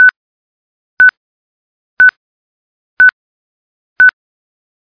1 channel
Clock.mp3